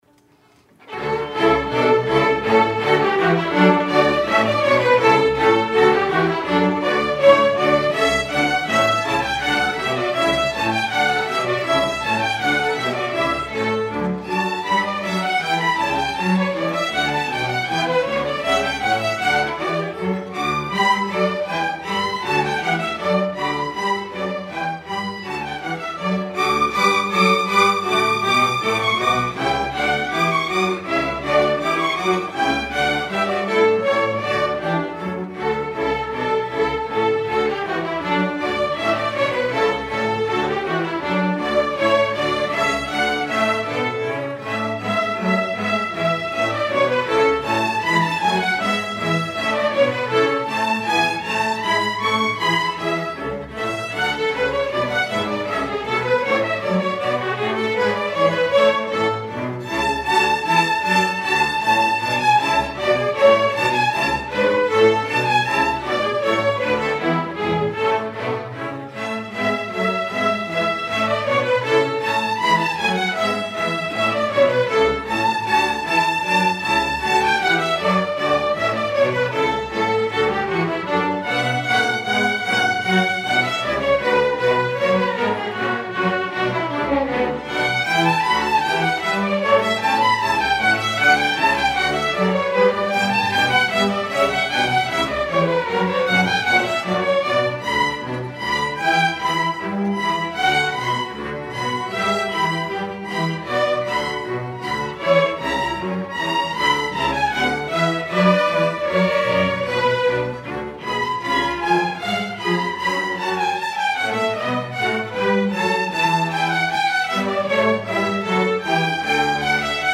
Queen City Community Orchestra
Fall 2014 Concert